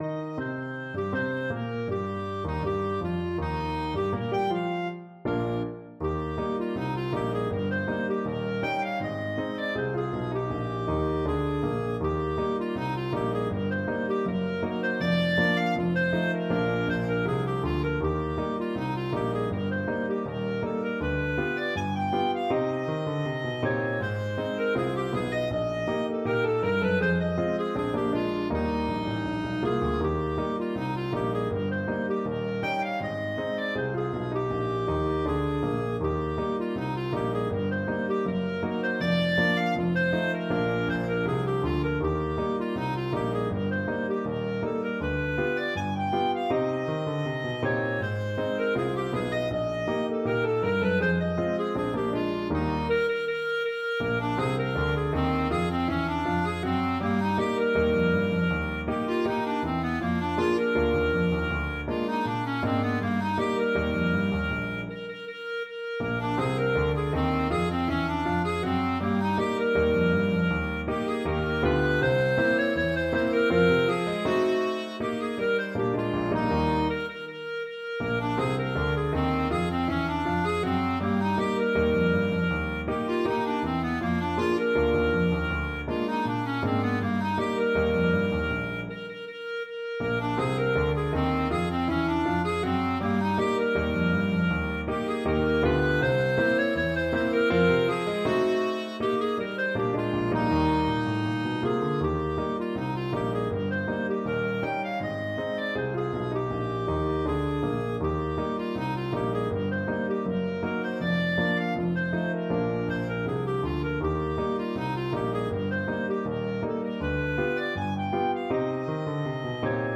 Jazz Scott Joplin Searchlight Rag Clarinet version
Clarinet
2/4 (View more 2/4 Music)
Slow March Tempo = 80
Bb4-Ab6
Eb major (Sounding Pitch) F major (Clarinet in Bb) (View more Eb major Music for Clarinet )
Jazz (View more Jazz Clarinet Music)